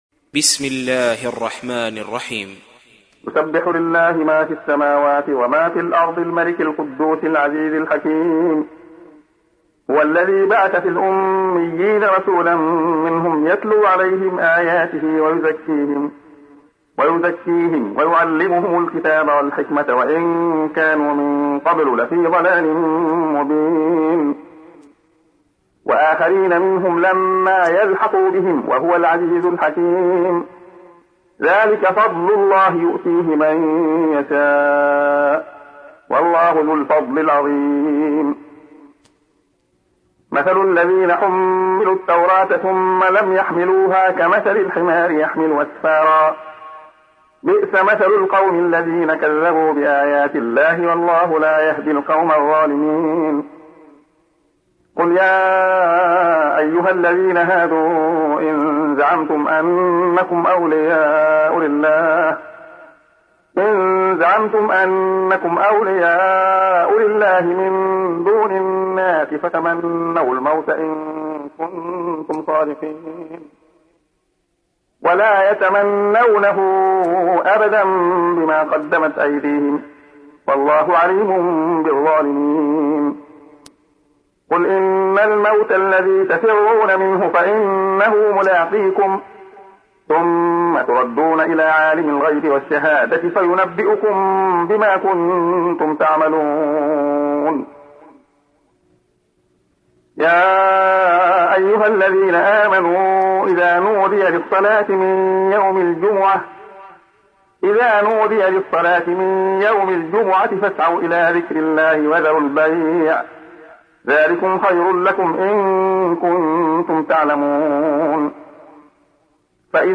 تحميل : 62. سورة الجمعة / القارئ عبد الله خياط / القرآن الكريم / موقع يا حسين